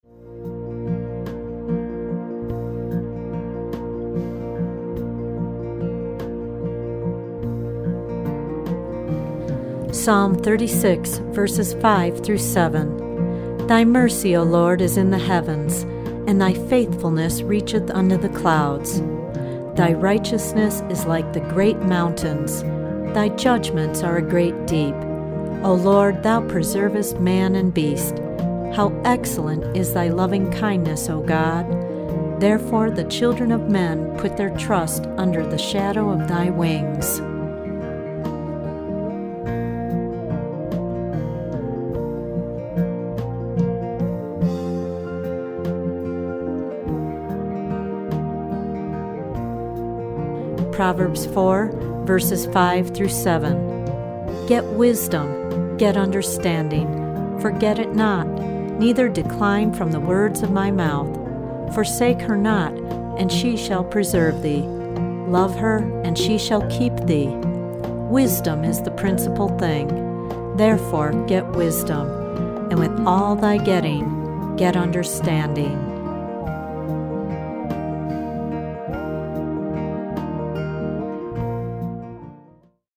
original anointed instrumental music on six CD’s.